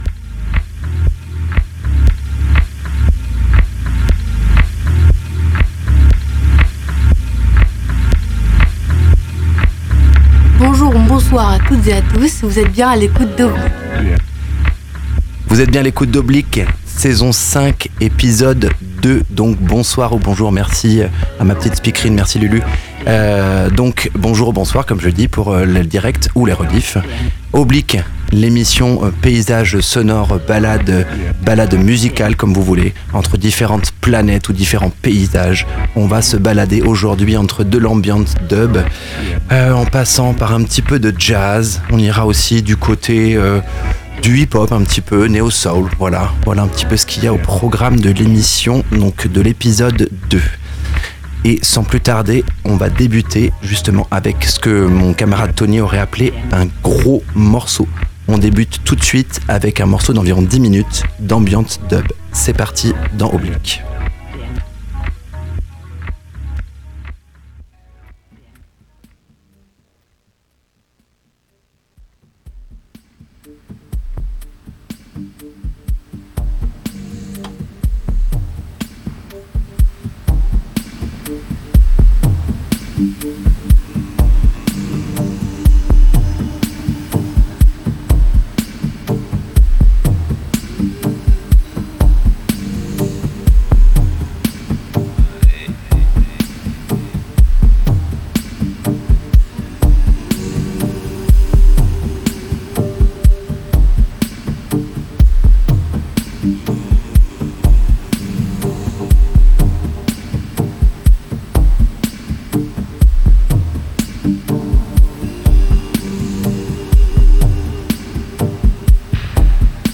ELECTRONICA